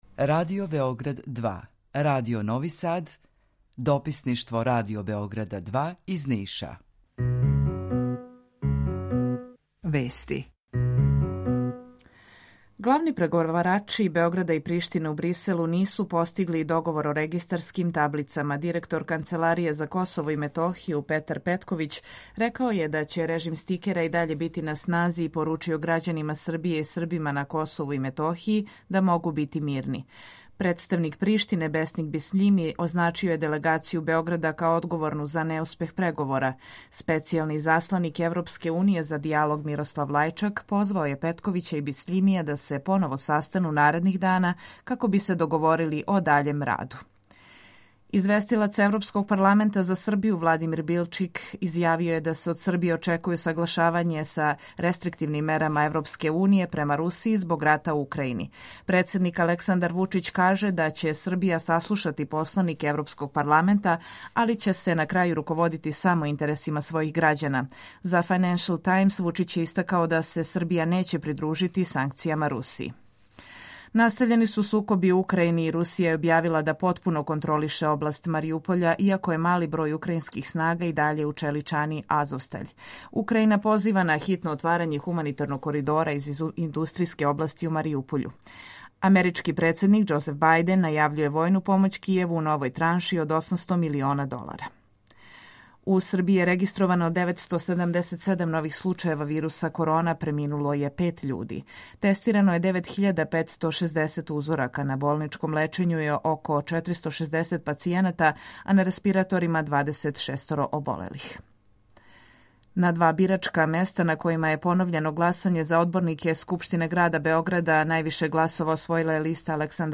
Јутарњи програм из три студија
Емисију реализујемо заједно са студиом Радија Републике Српске у Бањалуци и са Радио Новим Садом.
У два сата, ту је и добра музика, другачија у односу на остале радио-станице.